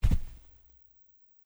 在积雪里行走的脚步声左声道－YS070525.mp3
通用动作/01人物/01移动状态/02雪地/在积雪里行走的脚步声左声道－YS070525.mp3
• 声道 立體聲 (2ch)